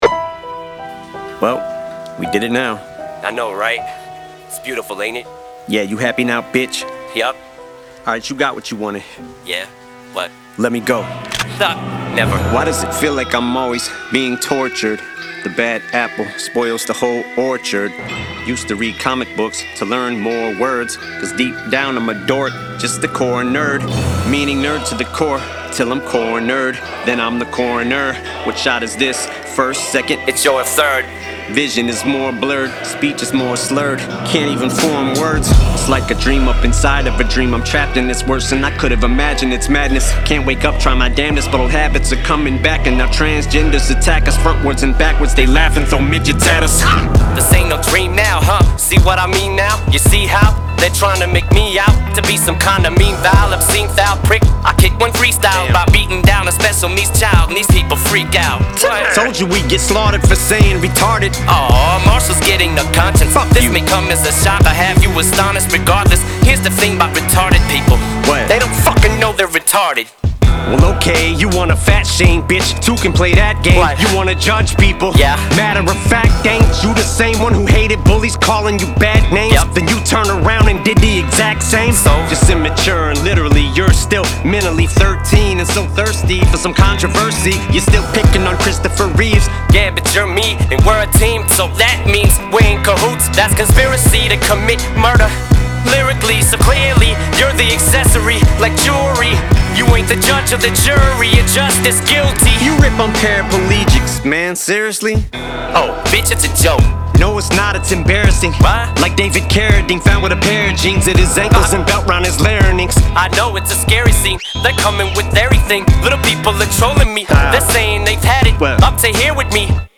• Жанр: Hip-Hop, Rap